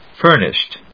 音節fúr・nished 発音記号・読み方
/ˈfɝnɪʃt(米国英語), ˈfɜ:nɪʃt(英国英語)/